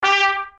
m_match_trumpet.ogg